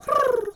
Animal_Impersonations
pigeon_2_call_12.wav